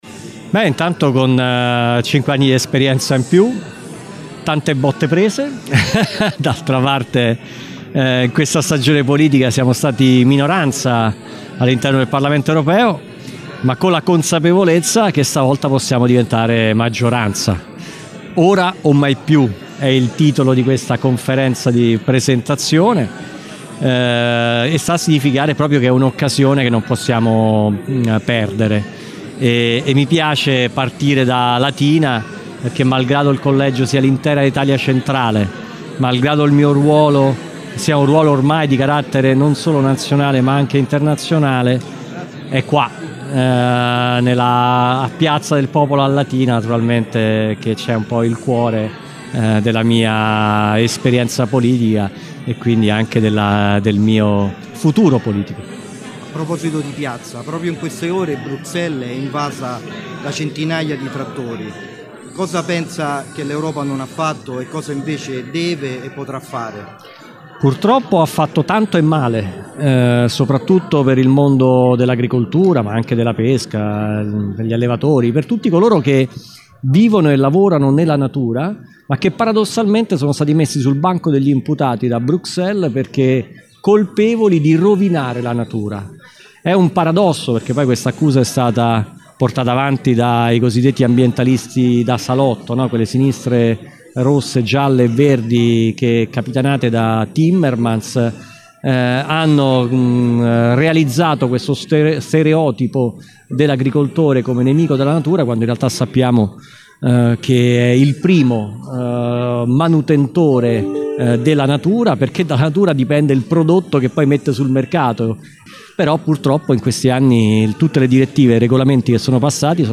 L’europarlamentare di Fratelli d’Italia di Terracina, proiettato verso il secondo mandato, ha ufficializzato la candidatura al Circolo cittadino accompagnato dal coordinatore provinciale Nicola Procaccini, dal consigliere regionale Vittorio Sambucci e dall’assessora Elena Palazzo.
Qui per Gr Latina, al microfono